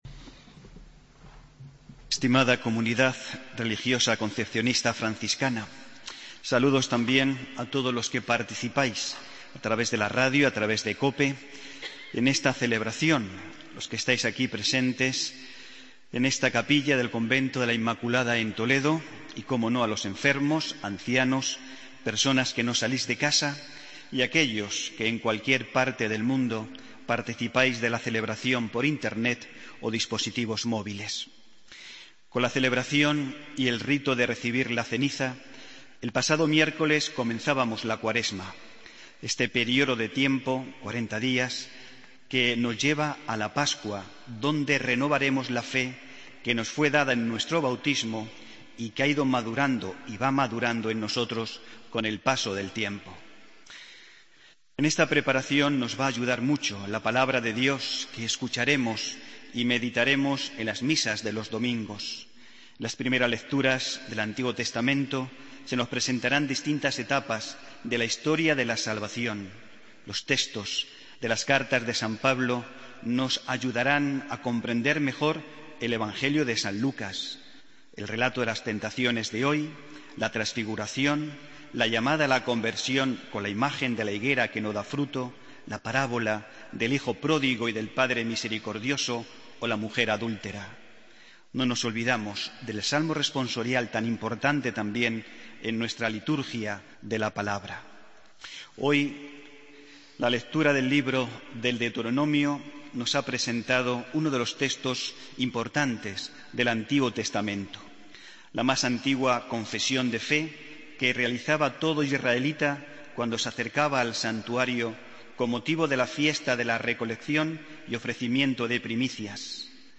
Homilía del domingo 14 de febrero de 2016